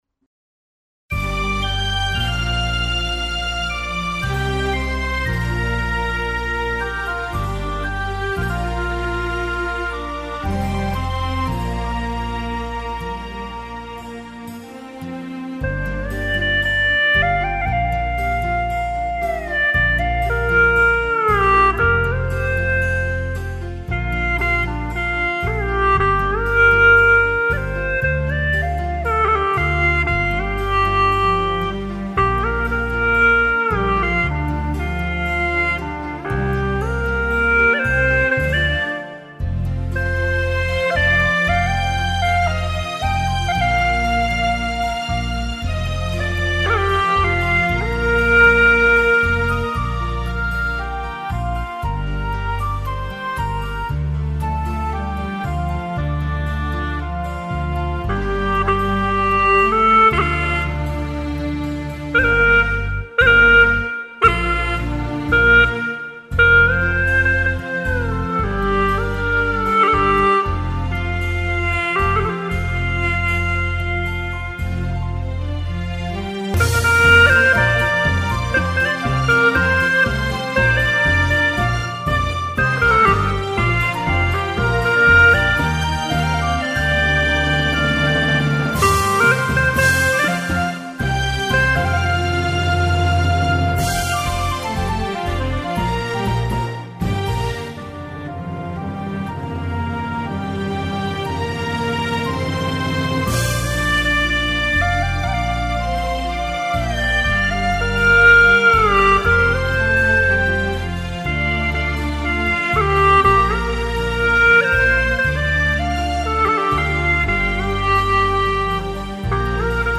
调式 : 降B